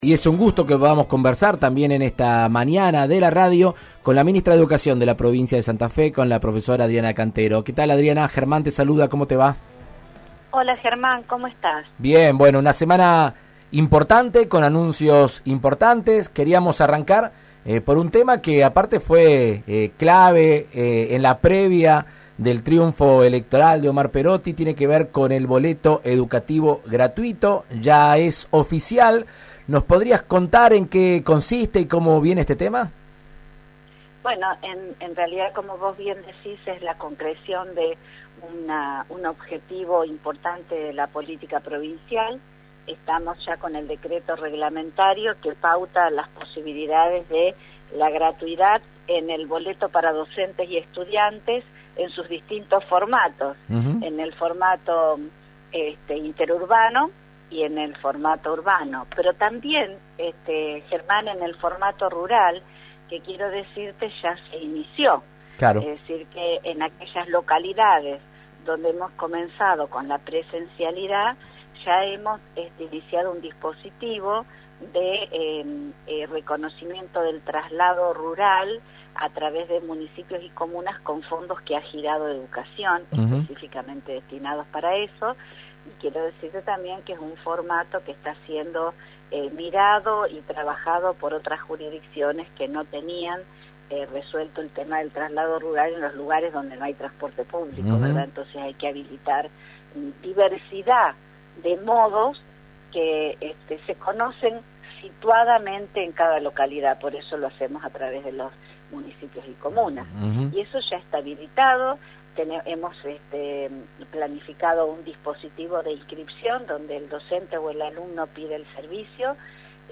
En Argentina Unida contra el Coronavirus, la ministra de educación de la provincia, Adriana Cantero, dialogó con el diputado nacional Germán Martínez acerca del boleto educativo gratuito que decretó el gobernador Perotti.